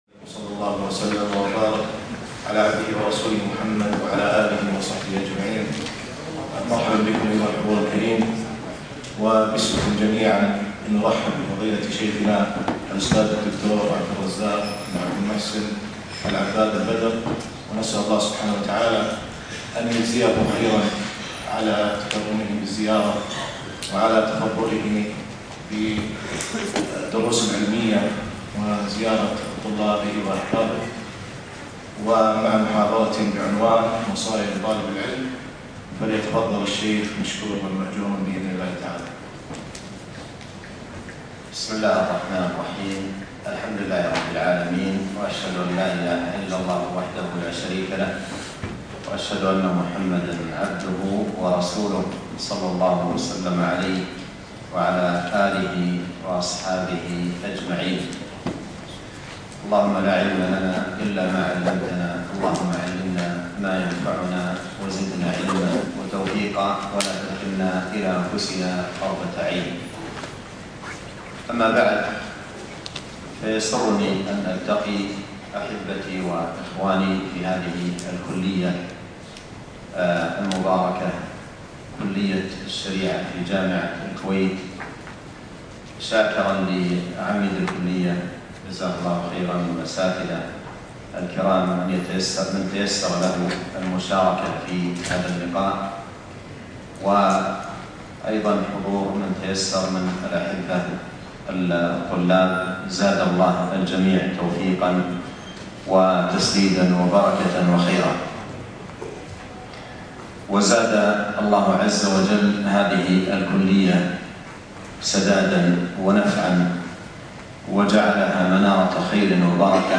وصايا لطالب العلم - محاضرة بكلية الشريعة